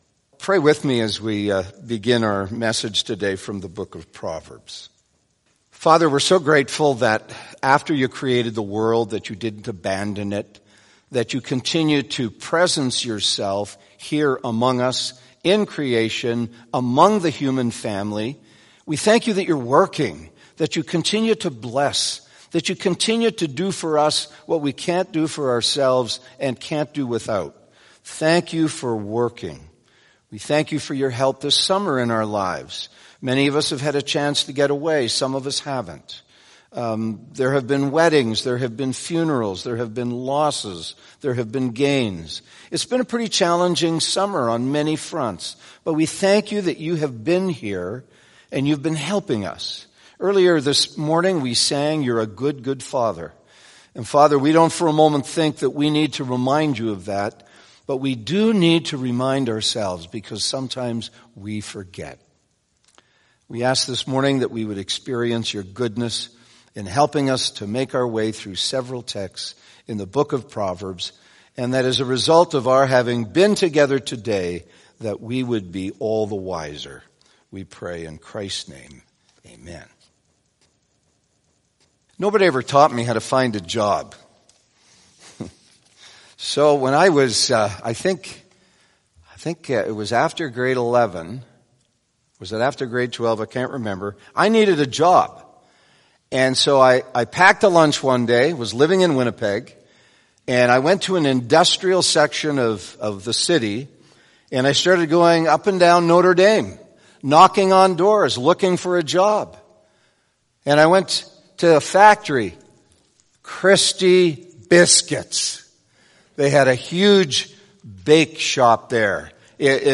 Sermons | Balmoral Bible Chapel